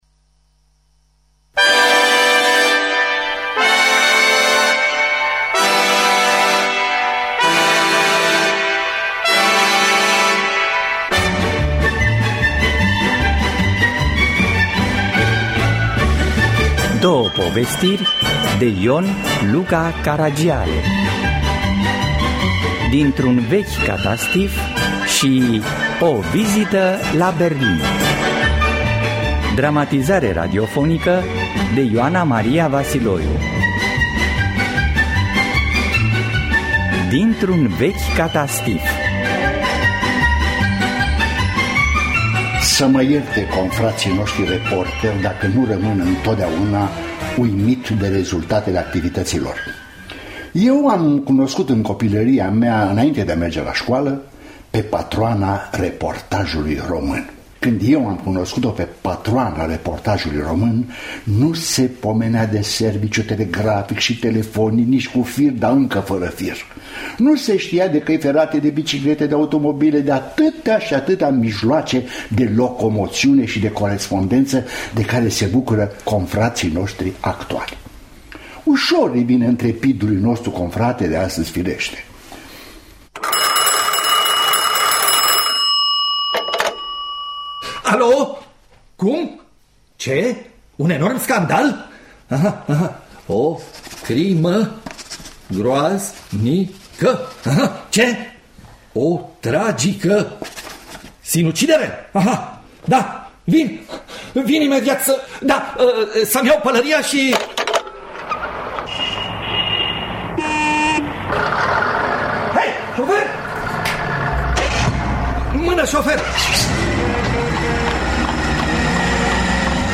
Dramatizare radiofonică